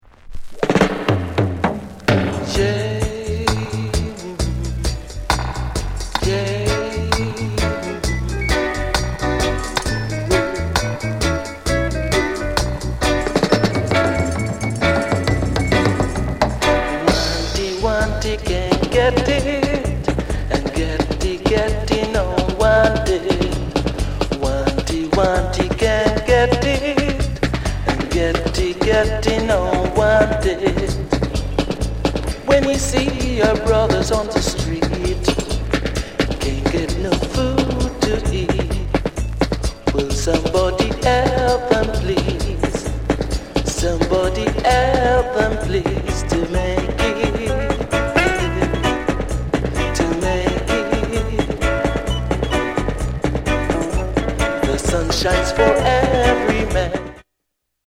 STEPPER ROOTS